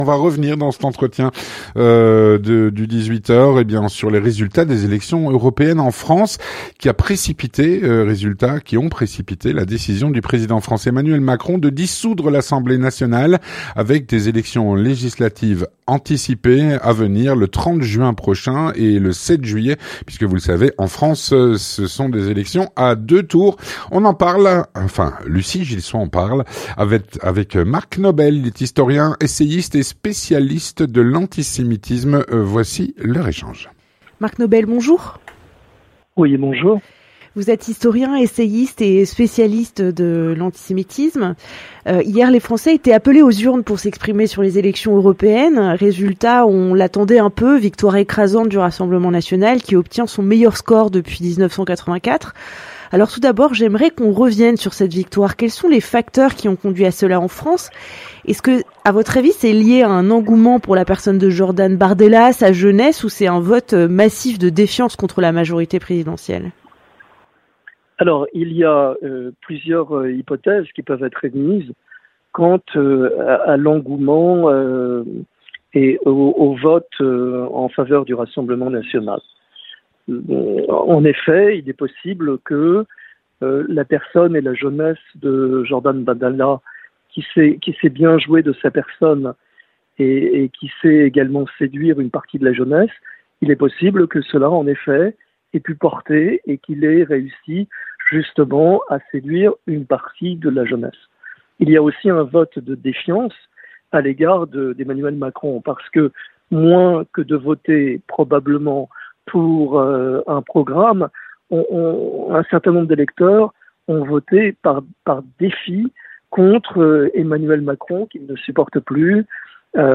L'entretien du 18H - Suite aux résultats des élections européennes en France, le Président français, Emmanuel Macron, a décidé de dissoudre l’Assemblée Nationale.